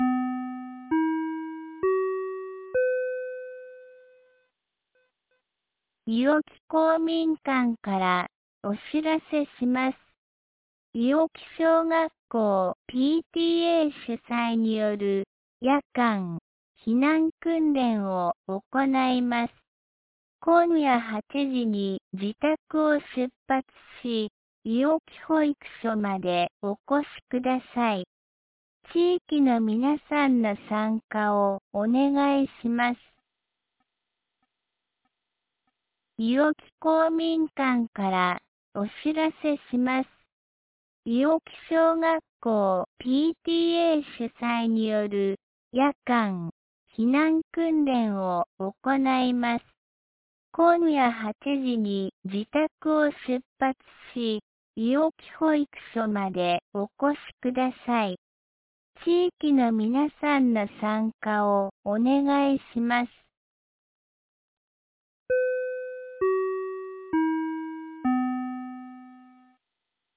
2026年02月28日 17時11分に、安芸市より伊尾木へ放送がありました。